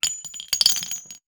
weapon_ammo_drop_16.wav